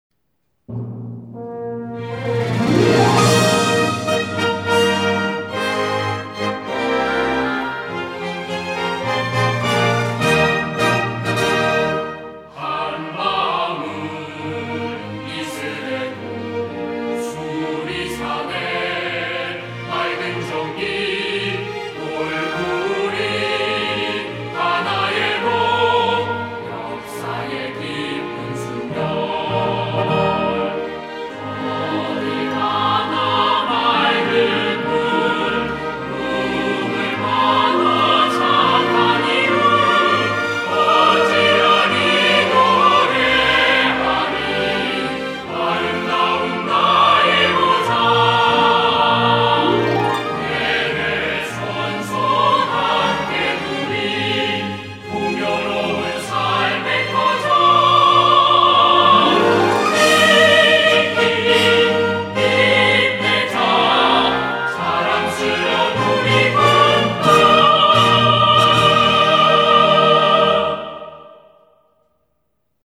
합창4성부(1절)버전
siga_chrous.mp3